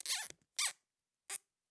household
Zip Ties Secure 2